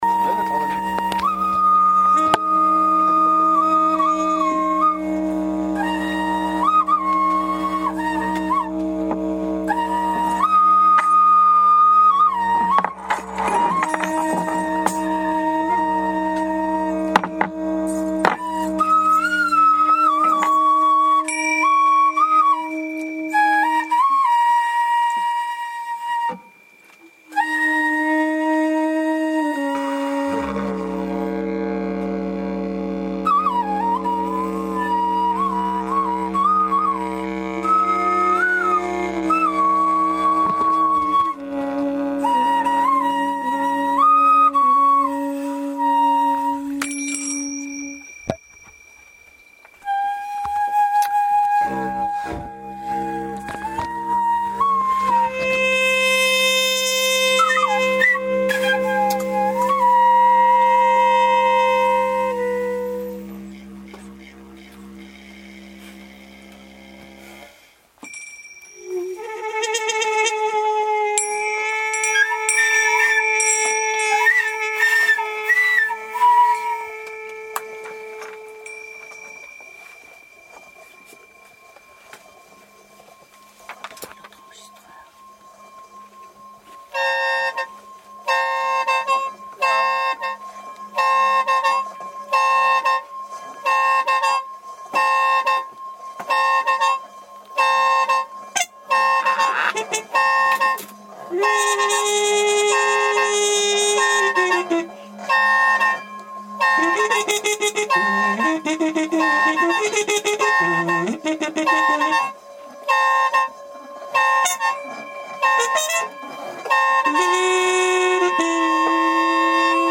Bol tibétain, Didjéridoo
Guibarde, flûte
Trompette